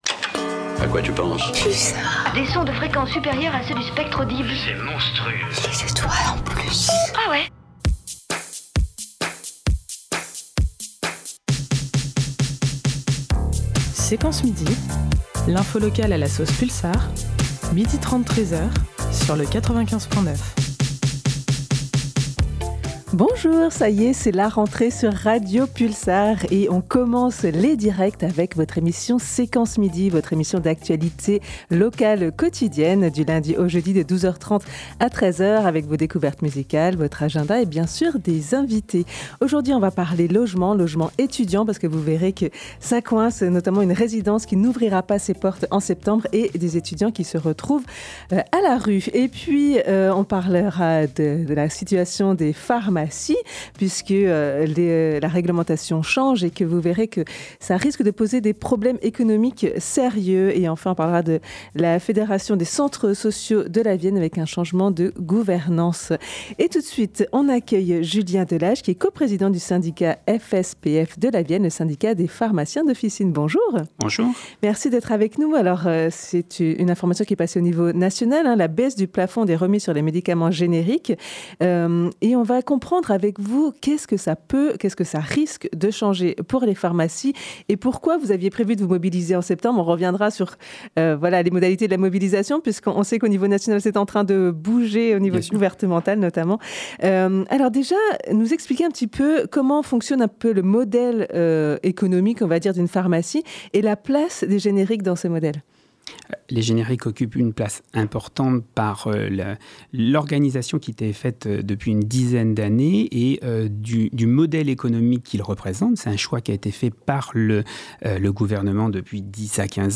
Ainsi que des chroniques, des reportages, des acteurs associatifs, etc…